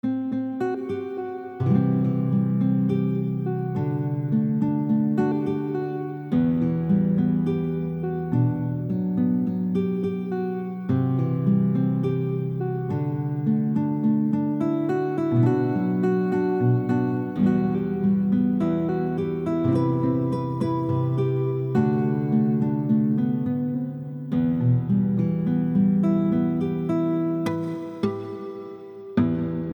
Audio de la tablature complète :
Accordage : Standard